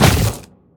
biter-roar-behemoth-3.ogg